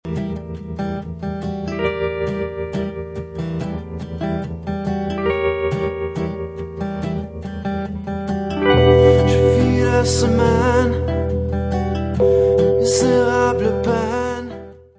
varietes influences reggae